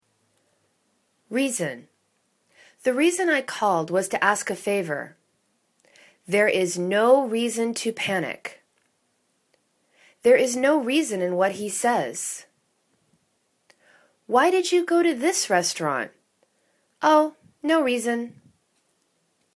rea.son /'ri:zәn/ [C]